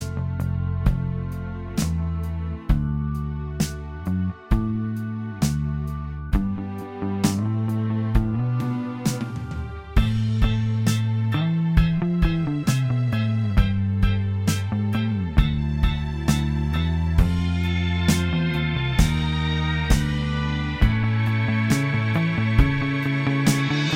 Minus Guitars Pop (1960s) 2:59 Buy £1.50